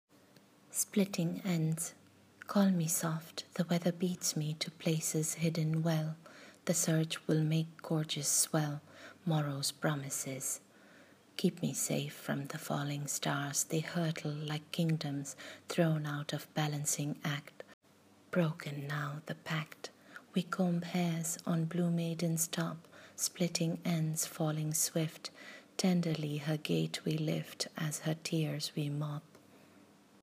Reading of the poem :